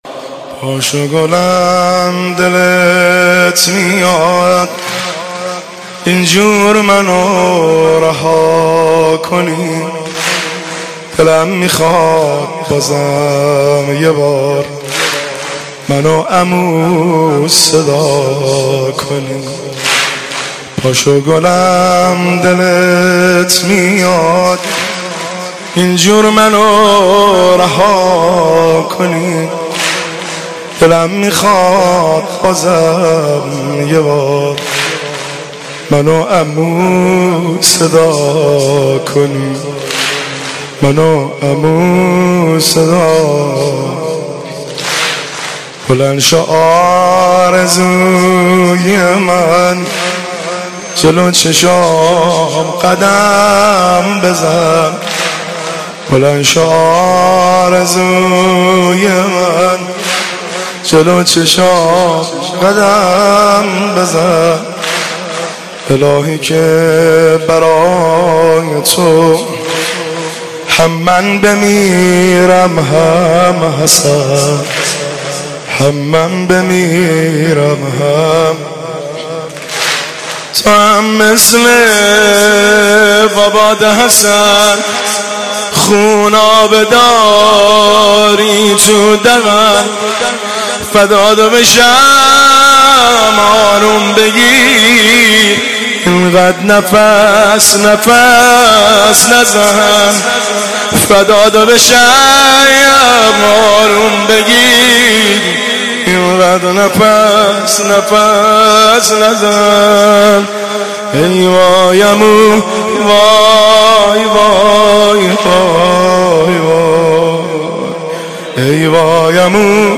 هیئت بین الحرمین طهران شب ششم محرم97